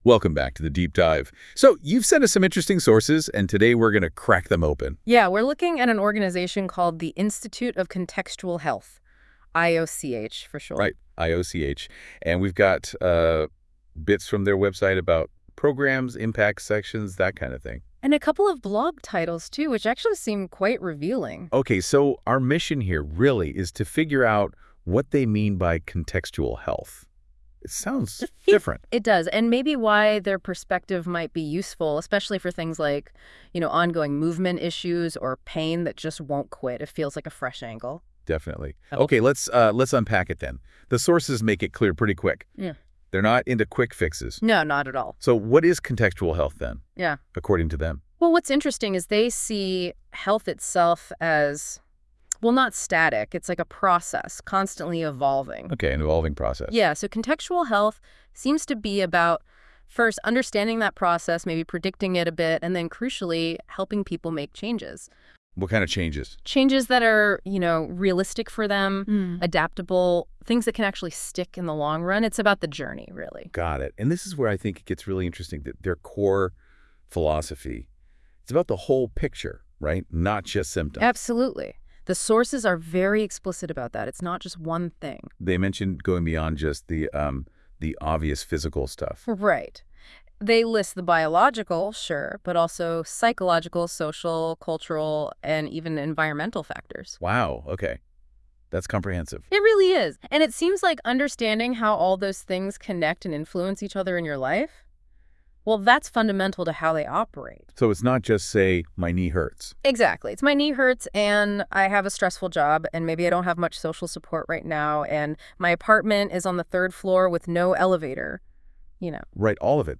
Podcast-style deep dive: Discover IOCH
Note: This podcast was created with NotebookLM to make IOCH’s philosophy accessible in a conversational way.